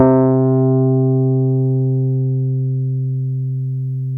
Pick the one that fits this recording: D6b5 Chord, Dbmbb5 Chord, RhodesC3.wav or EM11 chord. RhodesC3.wav